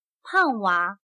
胖娃/Pàng wá/bebé gordo.